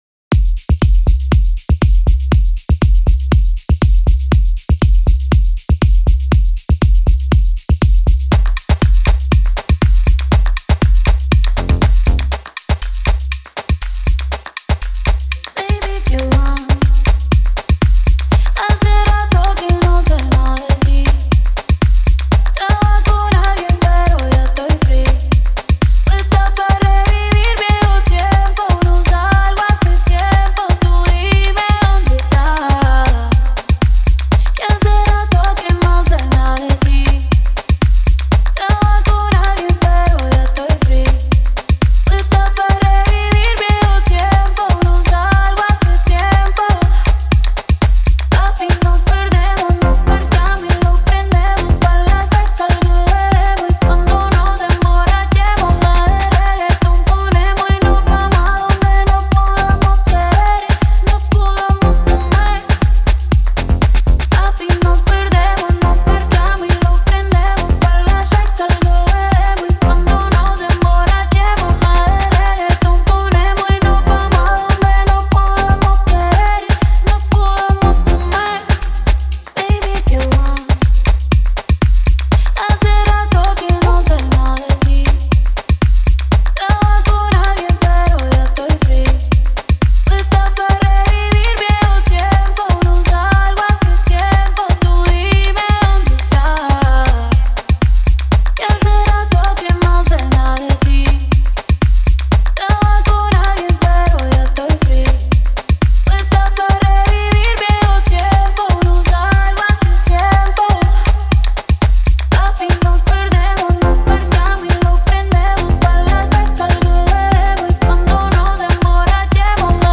a high-energy mix by Miami’s DJ & VJ